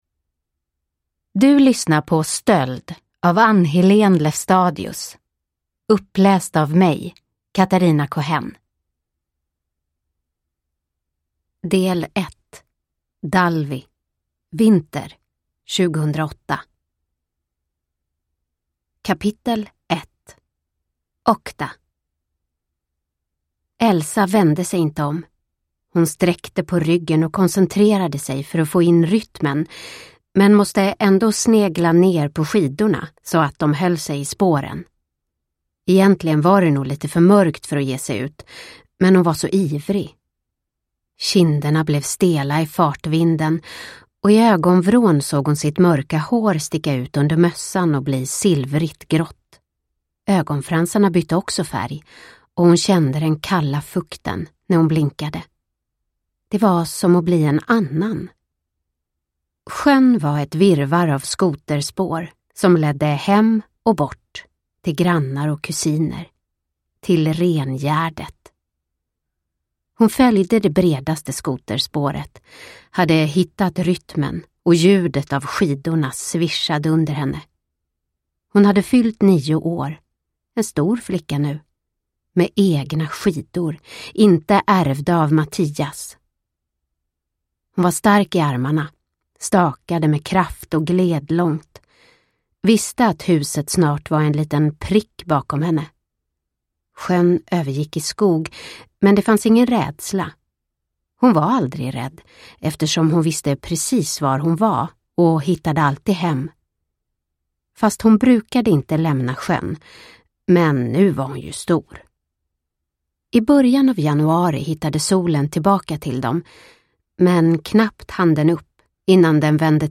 Stöld – Ljudbok – Laddas ner